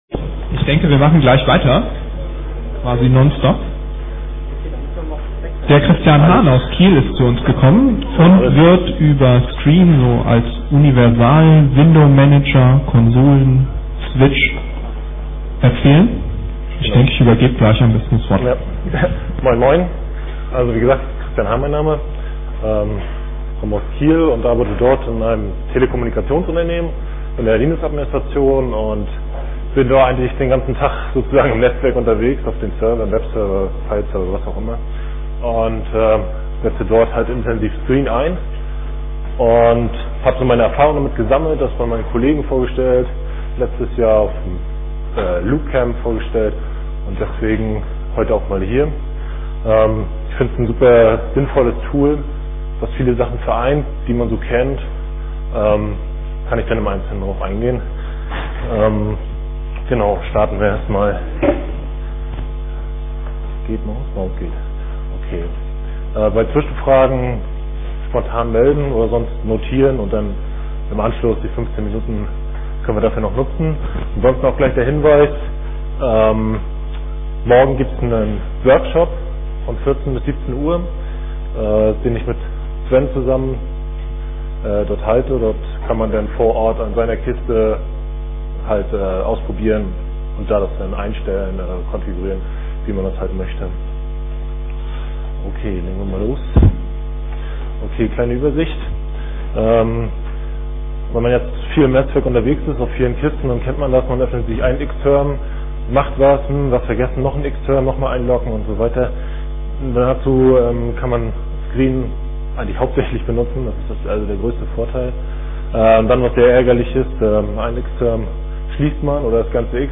Samstag, 11:00 Uhr im Raum V2 - Text-Tools
Vortragsmittschnitt